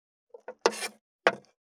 578魚切る,肉切りナイフ
効果音厨房/台所/レストラン/kitchen食器食材